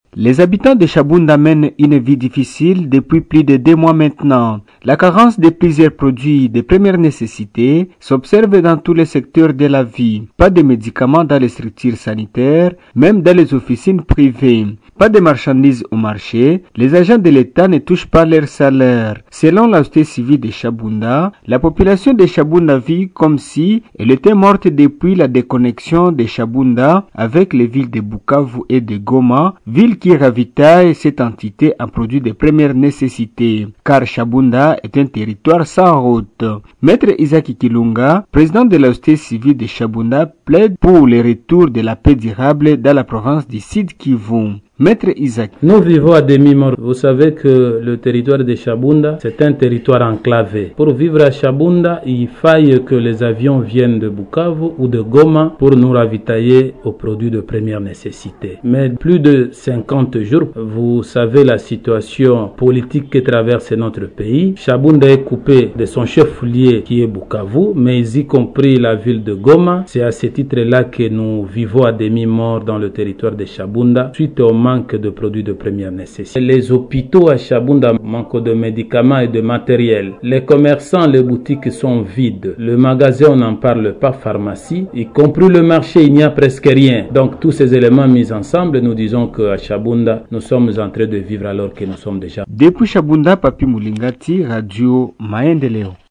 Crise sécuritaire au Sud-Kivu: le territoire de Shabunda en paie très cher (Reportage)